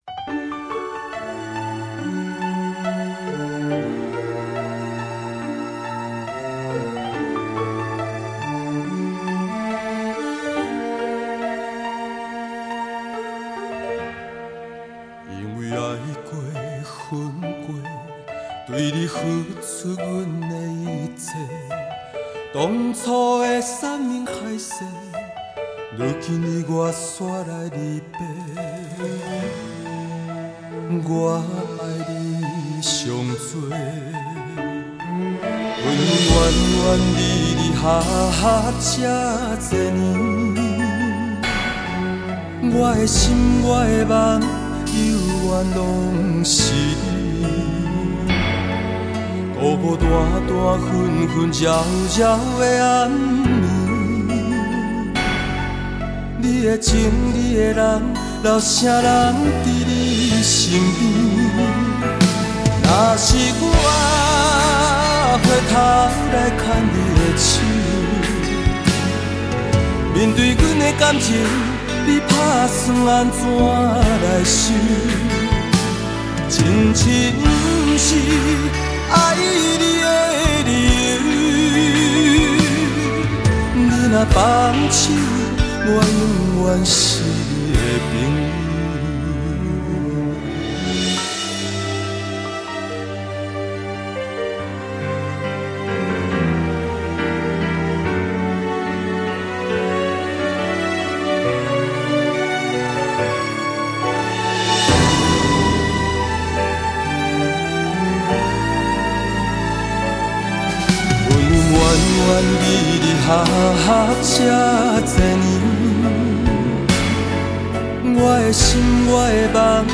这张专辑日本曲风浓烈。